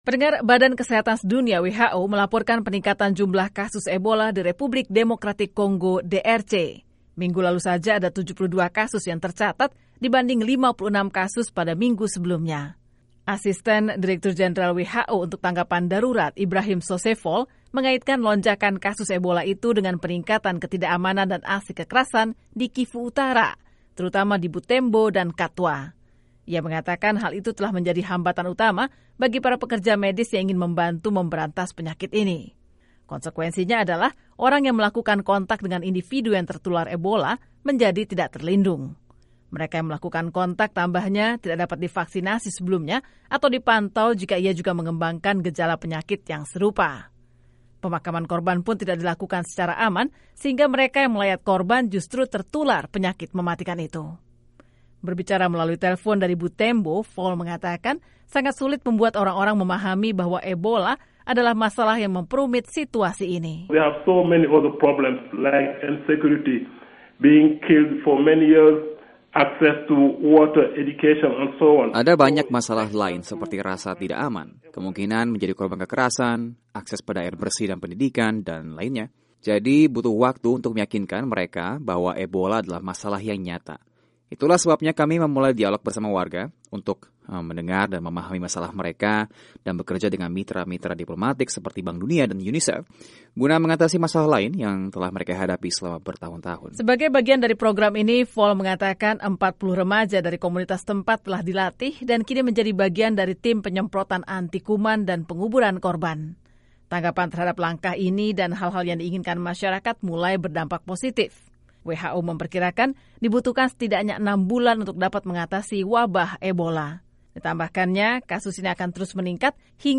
WHO mengatakan ketidakamanan dan ketidakpercayaan masyarakat di propinsi Kivu Utara di Republik Demokratik Kongo DRC yang dilanda konflik merupakan hambatan utama upaya tim medis internasional untuk memberantas wabah Ebola yang mematikan. Berikut laporannya.